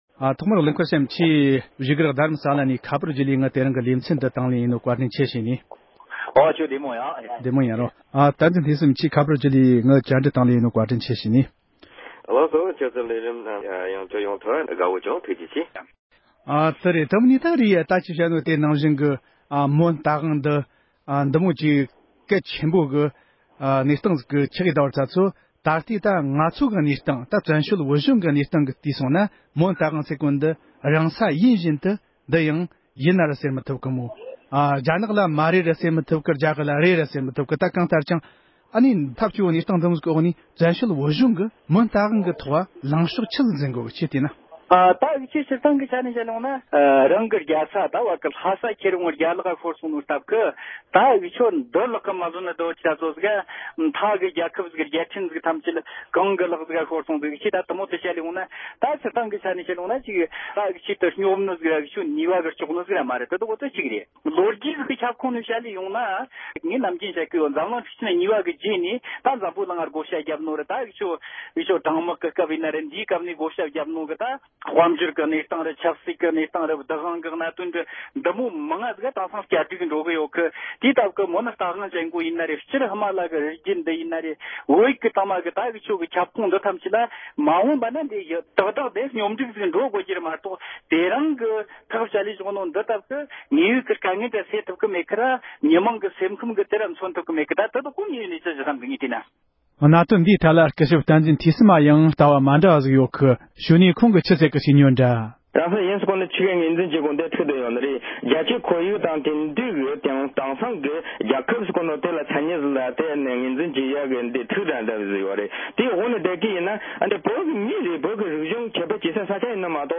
མོན་རྟ་དབང་གི་བྱུང་རིམ་དང་ལོ་རྒྱུས་ཐད་བགྲོ་གླེང༢པ།